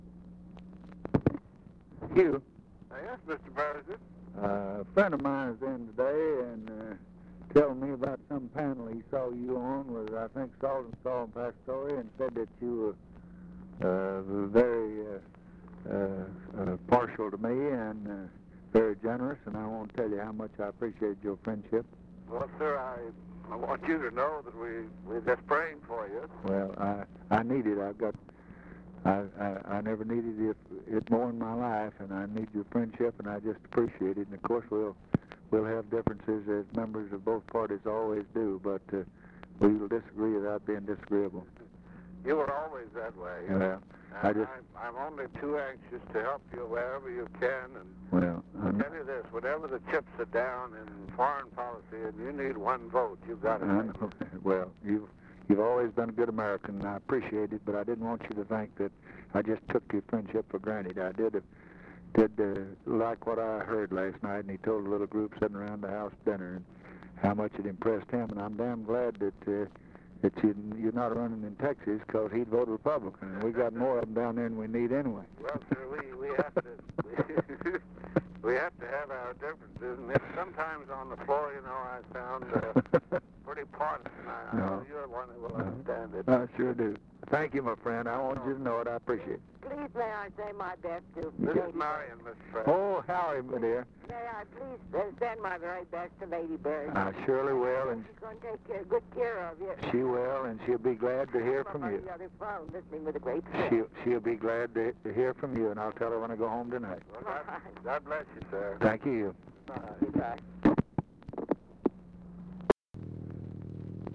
Conversation with HUGH SCOTT, November 29, 1963
Secret White House Tapes